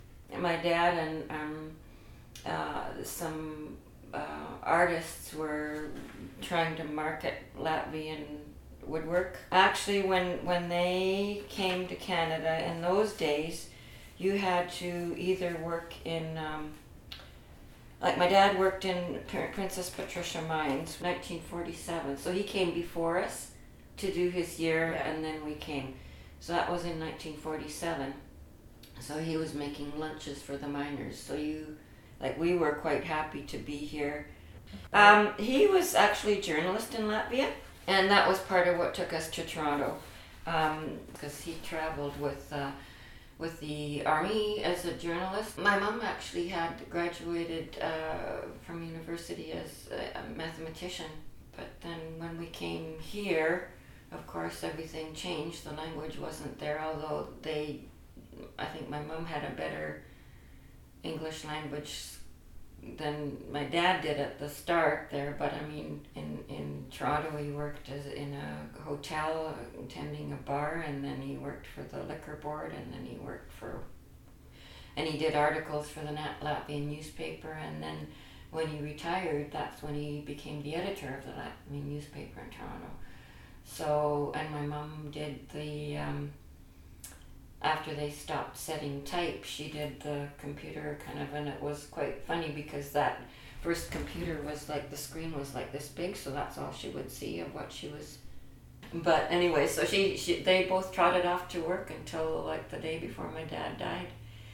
audiotapes
Interviewer (ivr)